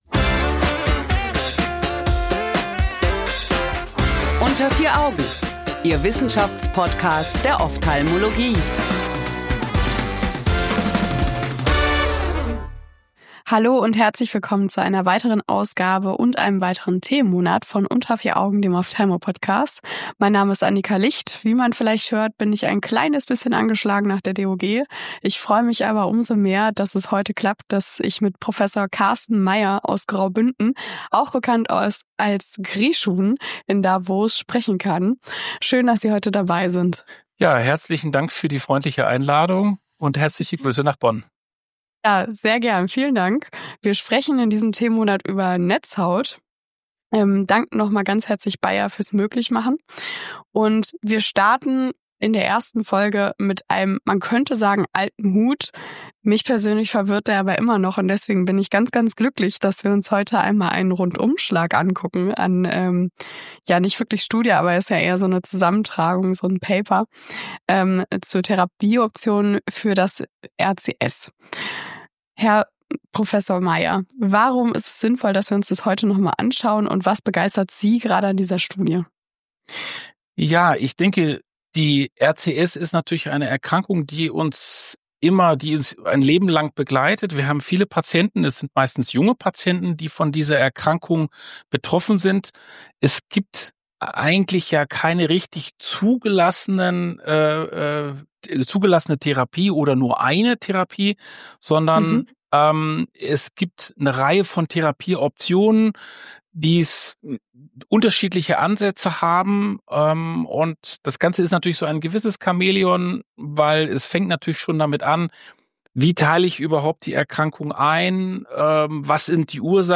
Welche Therapieansätze aktuell empfohlen werden und wo noch Unsicherheiten bestehen, besprechen wir im heutigen Gespräch.